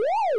siren0.wav